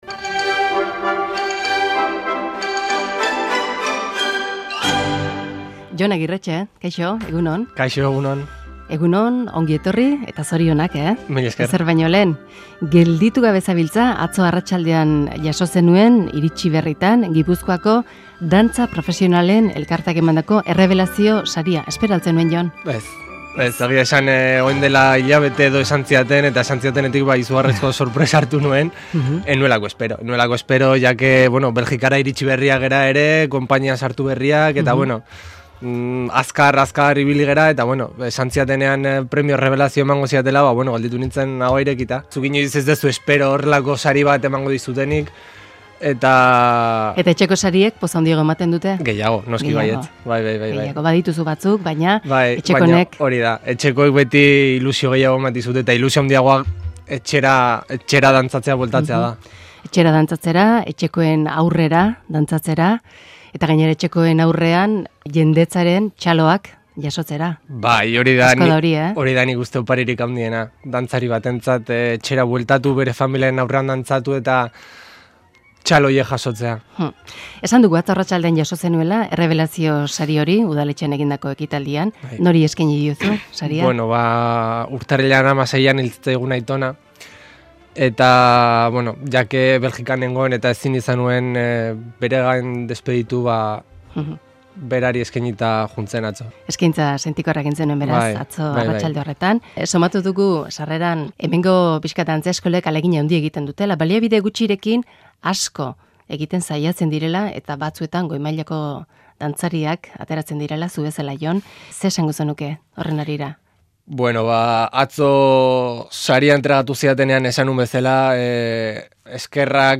elkarrizketa Euskadi Irratian, Amarauna saioan